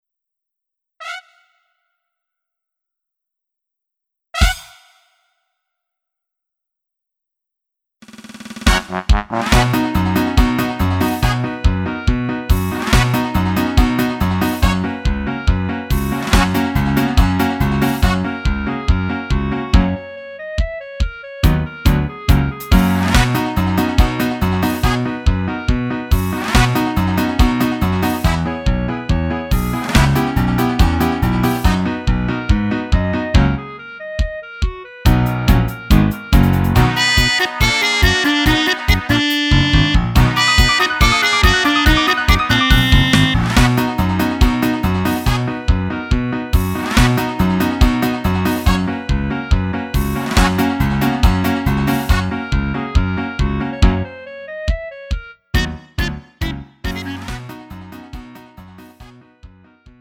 음정 원키 3:56
장르 구분 Lite MR